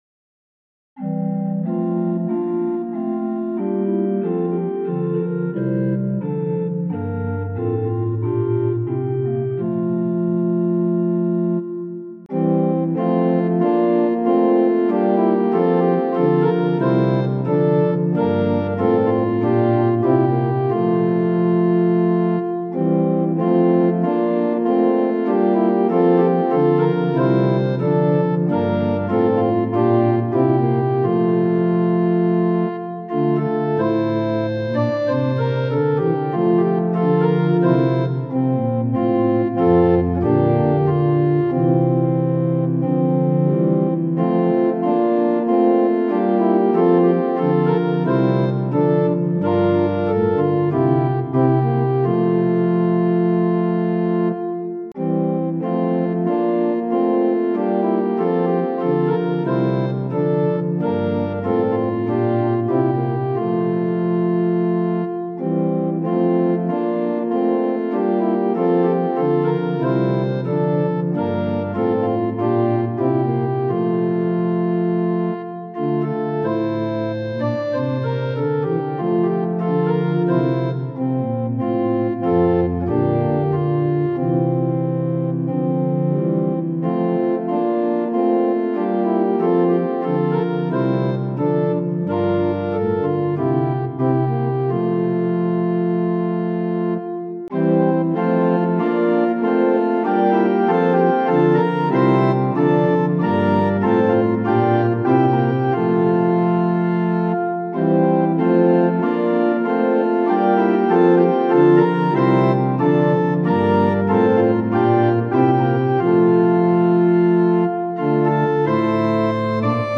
Tonality = F Pitch = 440 Temperament = Equal